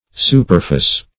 \Su"per*fice\
superfice.mp3